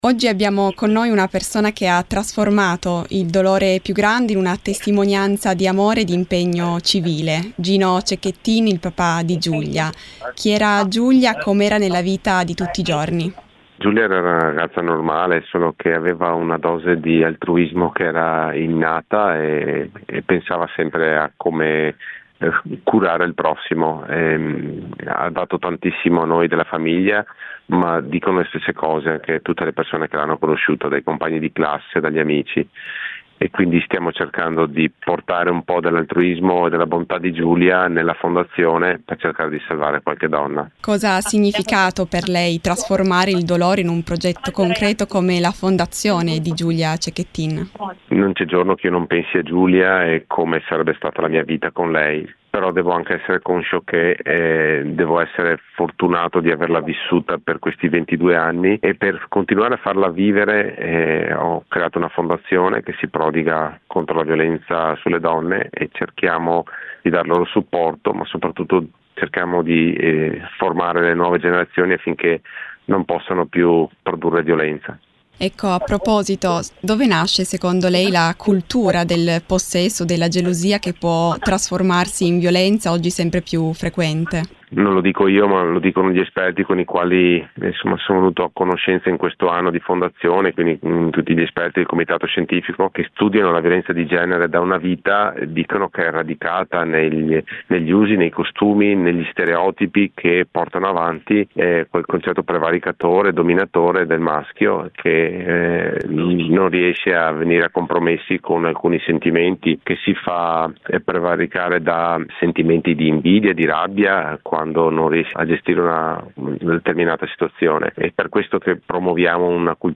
Al Taste of Earth di Isola della Scala, presso la Show Time Arena Palariso, Gino Cecchettin è stato ospite speciale, condividendo una testimonianza profonda, intrisa di amore, resilienza e speranza.